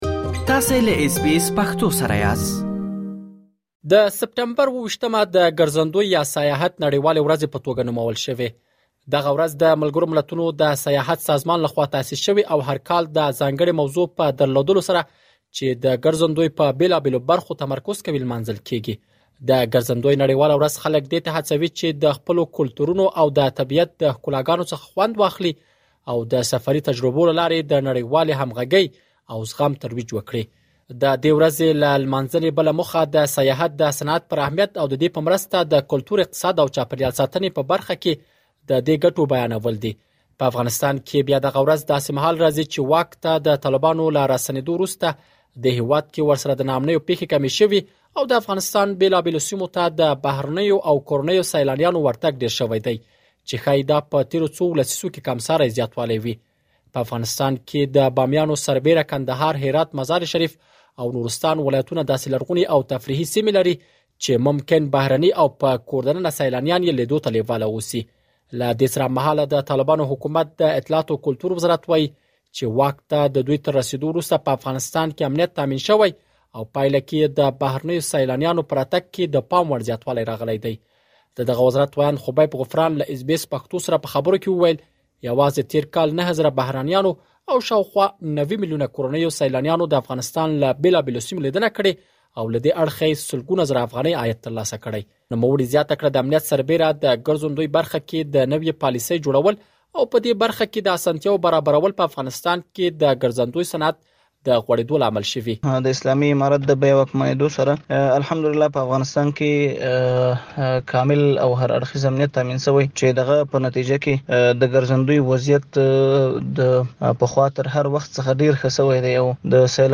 لا ډېر معلومات په رپوټ کې اورېدلی شئ.